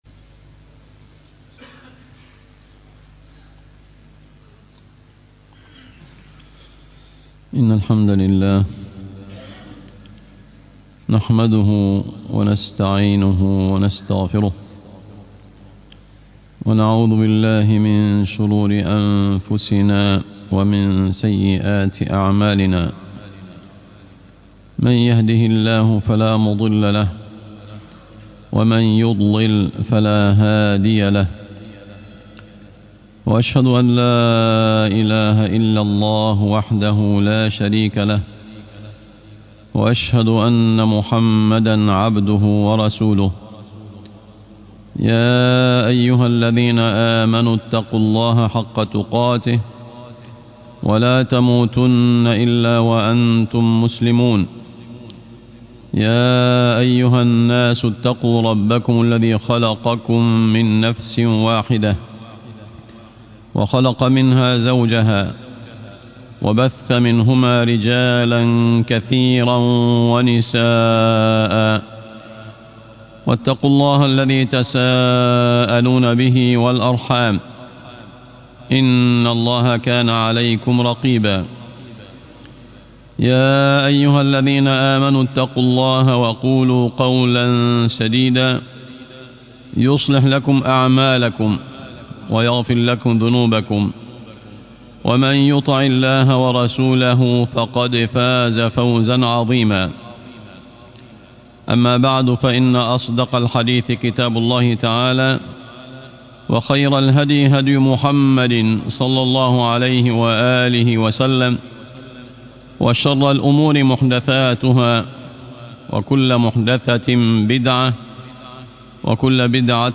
الحياء زينة الإنسان - خطب الجمعة